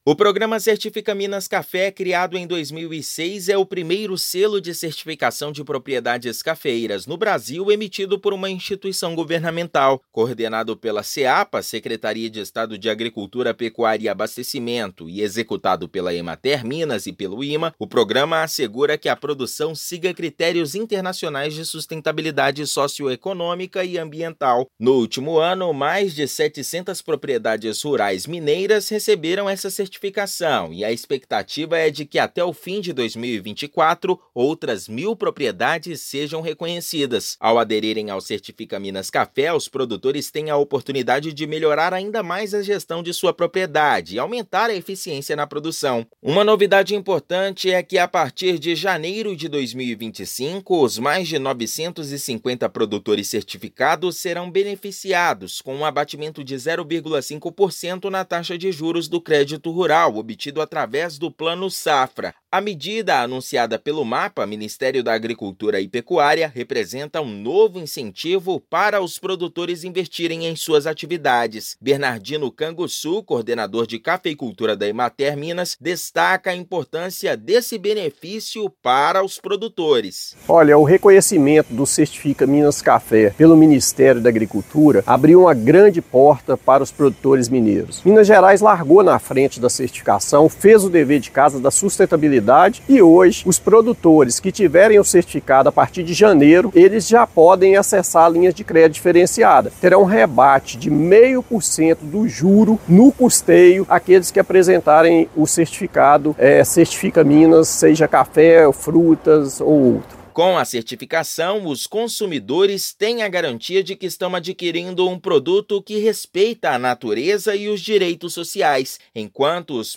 Em 2025, mais de 950 produtores certificados serão beneficiados com abatimento de 0,5% na taxa de juros do crédito rural, incentivando ainda mais a adesão ao programa. Ouça matéria de rádio.